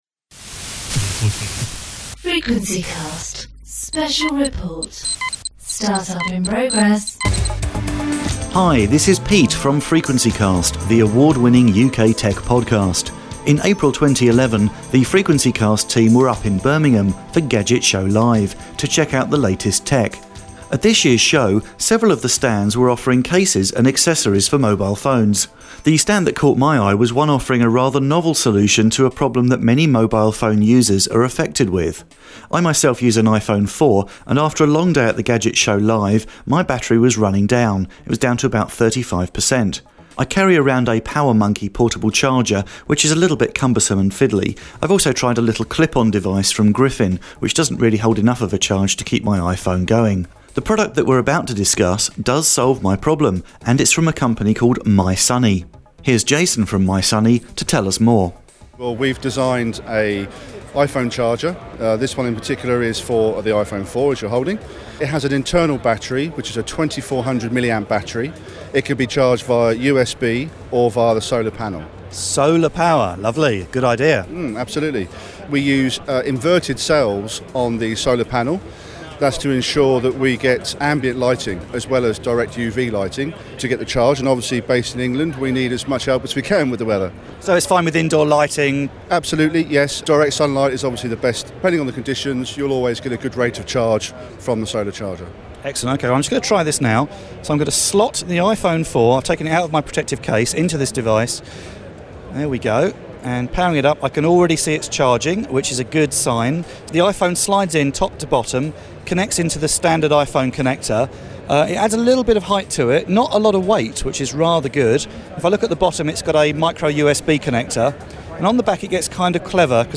Our Interview with Mi Suny